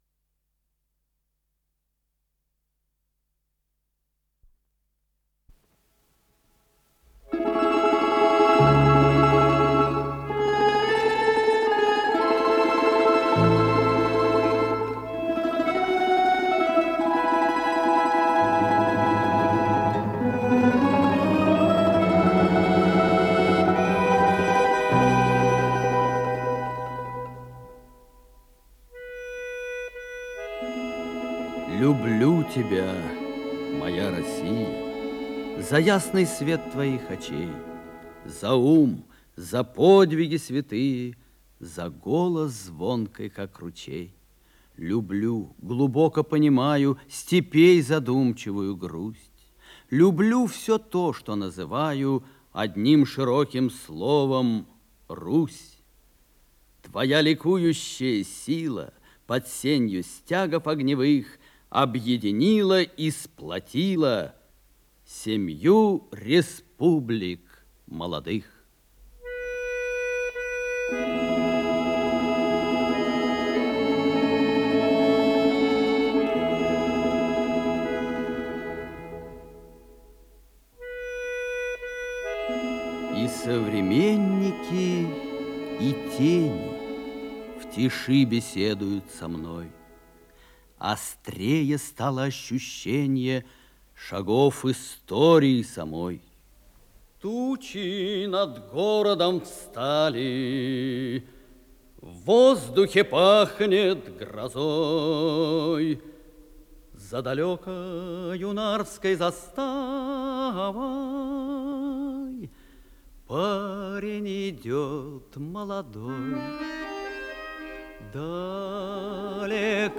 Литературно-музыкальная композиция